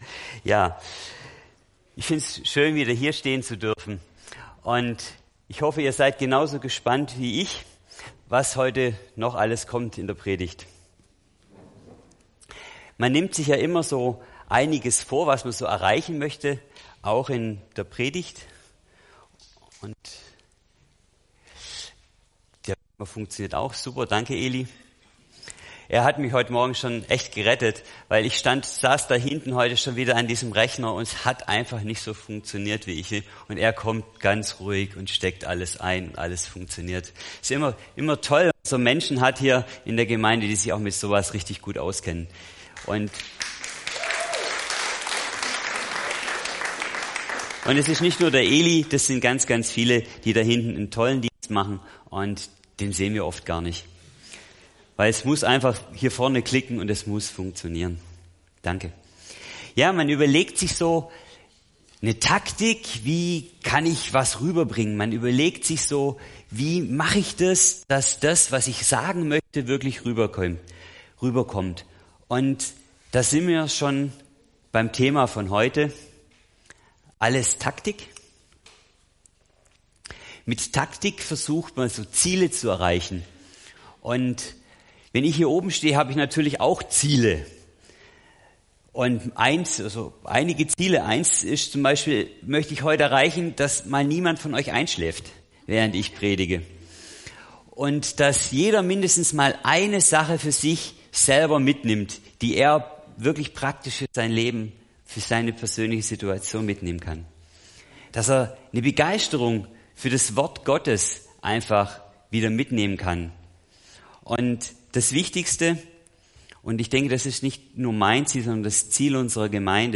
Das Begleitmaterial zur Predigt kann unter diesem Link herunter geladen werden.